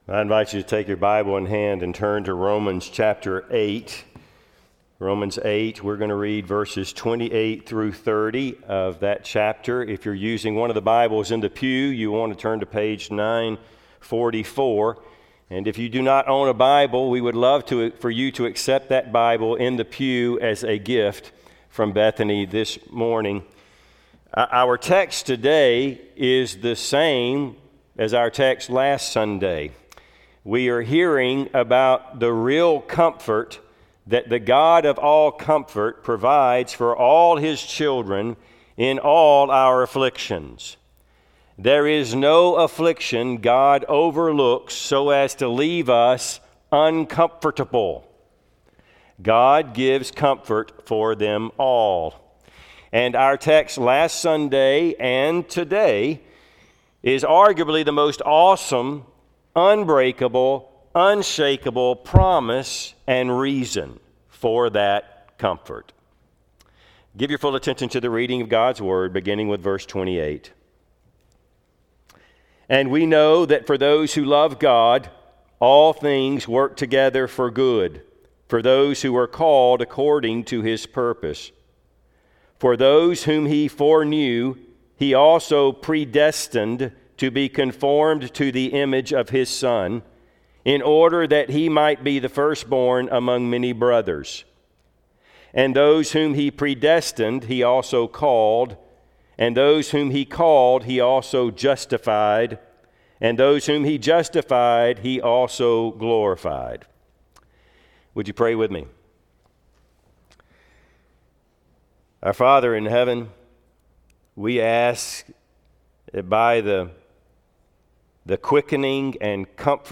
Service Type: Sunday AM Topics: God's Sovereignty , Salvation , Suffering